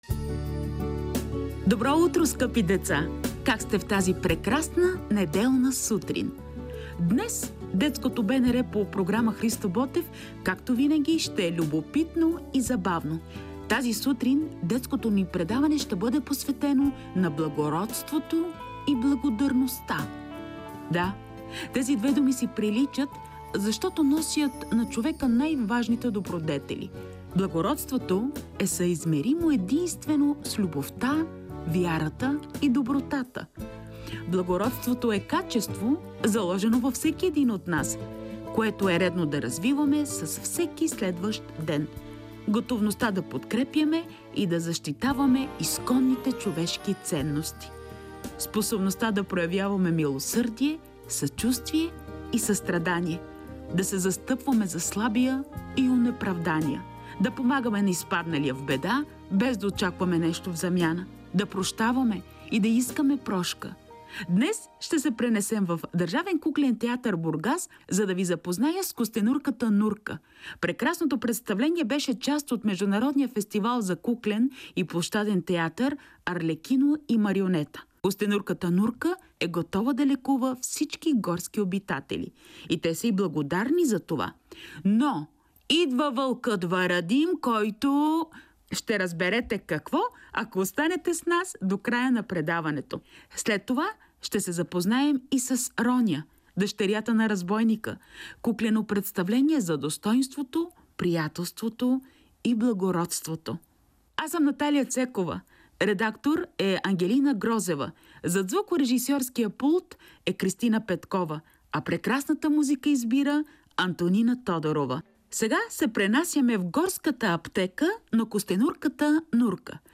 Скъпи деца, днешното ни детско предаване ще бъде посветено на благородството и благодарността.
Днес ще се пренесем в Държавен куклен театър – Бургас , за да се запознаем с „Костенурк а Нурка “ . Прекрасното представление беше част от Международния фестивал за куклен и площаден театър „Арлекино и Марионета“ . Костенурката Нурка е готова да лекува всички горски обитатели.